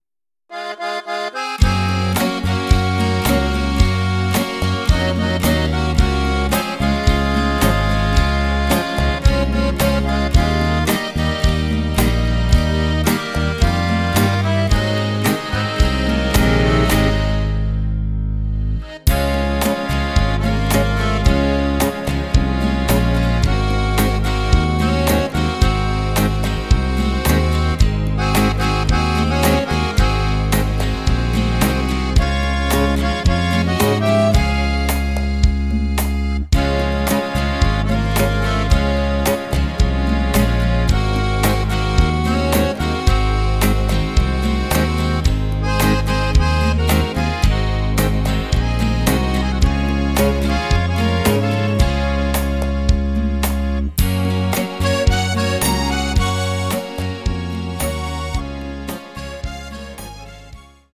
sehr melodiöses Lied